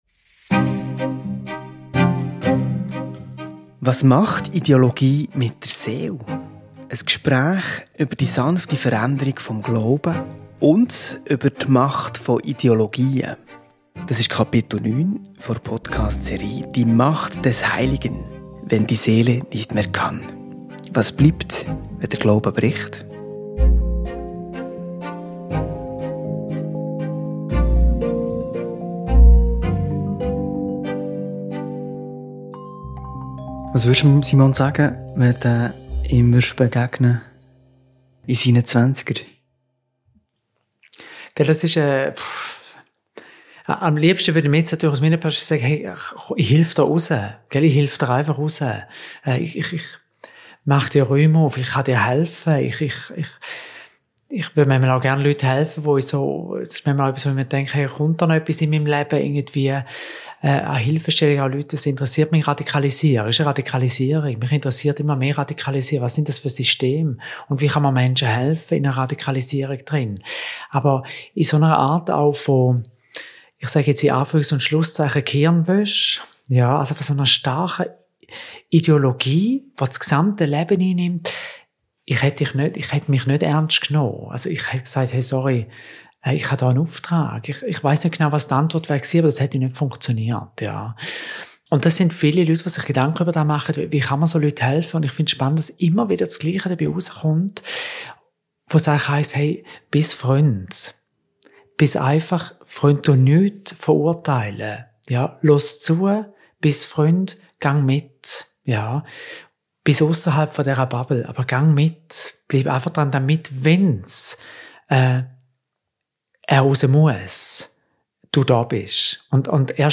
Was macht Ideologie mit der Seele? Ein Gespräch über die sanfte Veränderung des Glaubens.